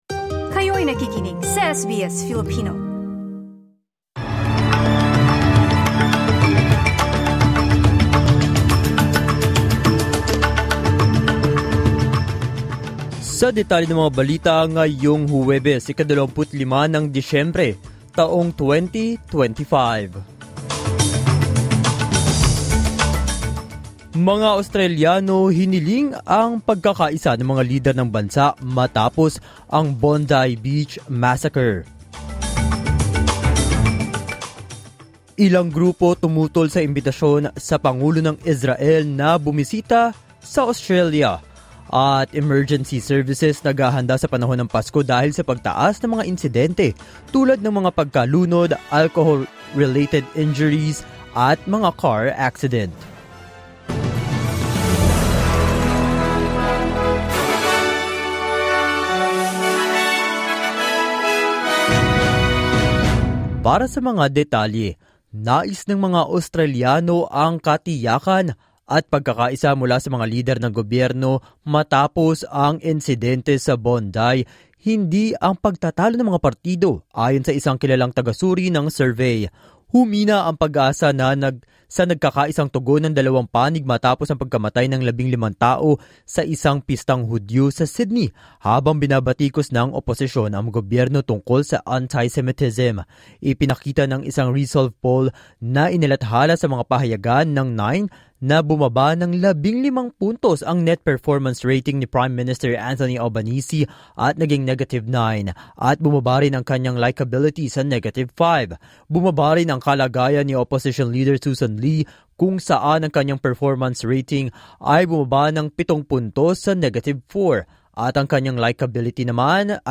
SBS News in Filipino, Thursday 25 December 2025